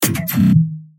UMRU_drum_fill_popular_lasers_01_119
nuest-laser.mp3